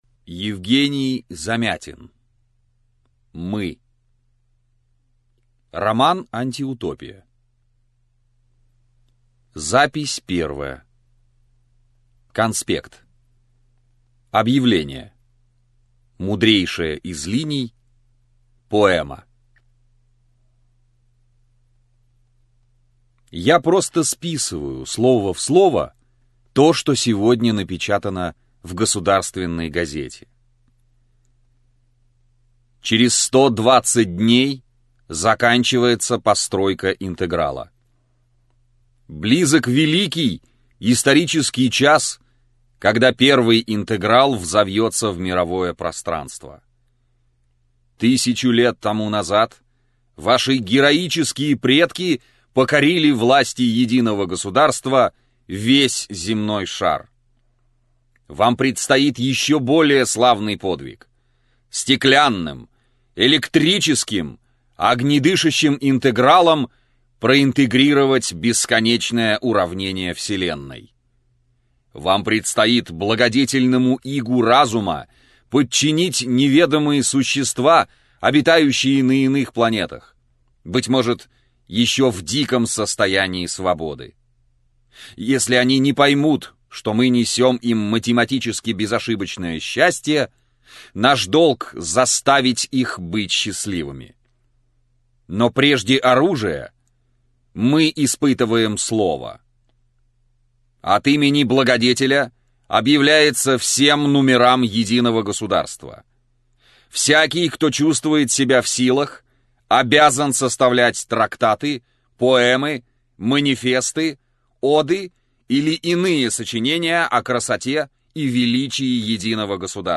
Аудиокнига Мы - купить, скачать и слушать онлайн | КнигоПоиск